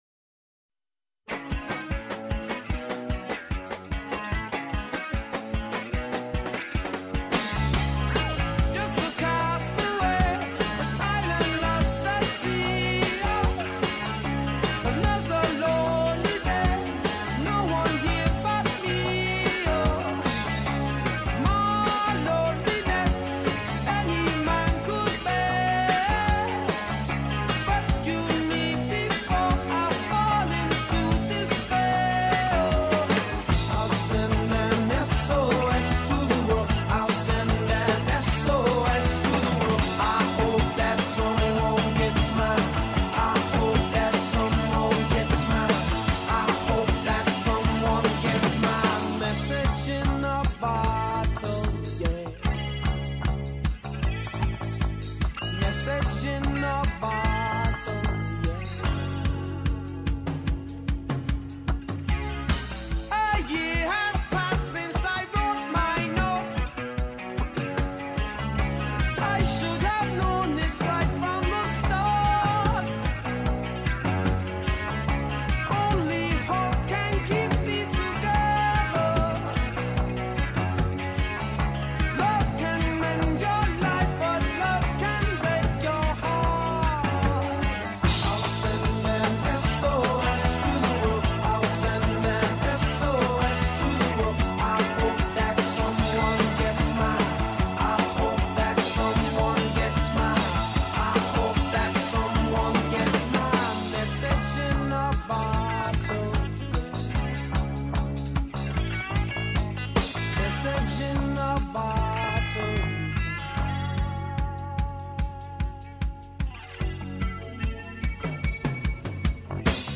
New Wave, Reggae Rock, Post-Punk, Rock